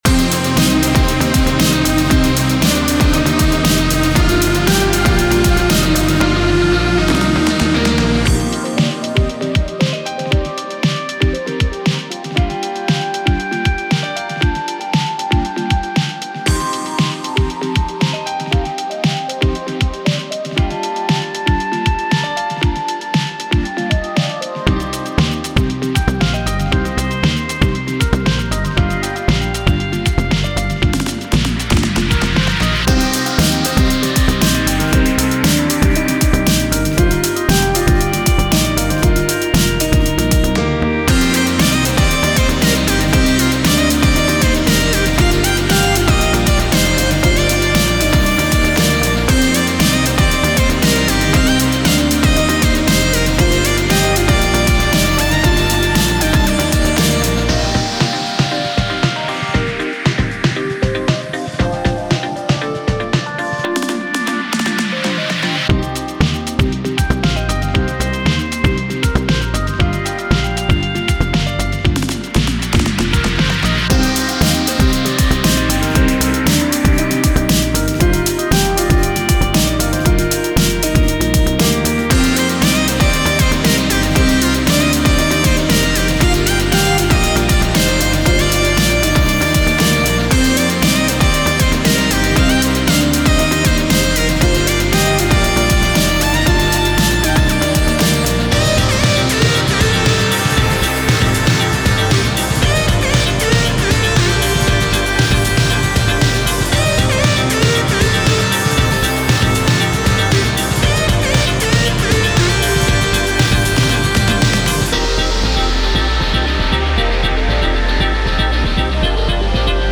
Nothing like a song that captures the essence of motion, energy, and the electric atmosphere of a lively, upbeat environment.
fast energetic lively upset electronic